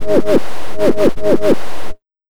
BIG125NOIS-L.wav